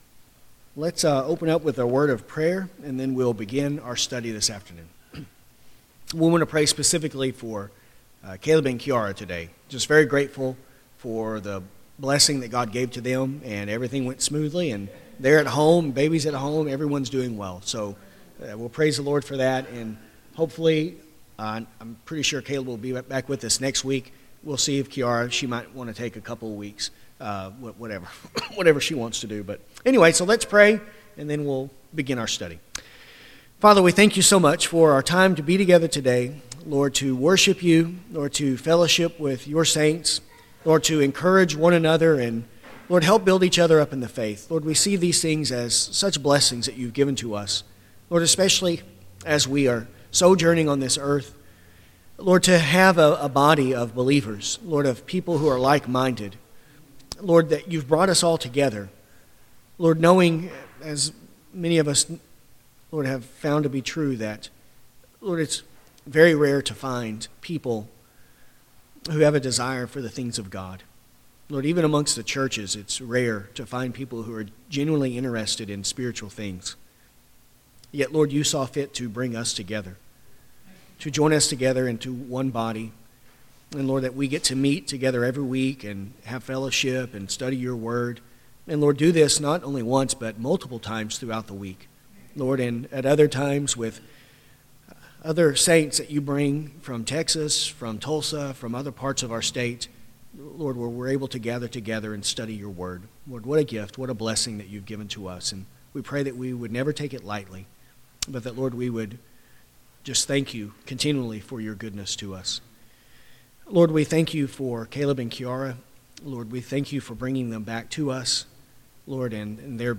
This lesson covers paragraphs 1.4 – 1.5. To follow along while listening, use the link below to view a copy of the confession.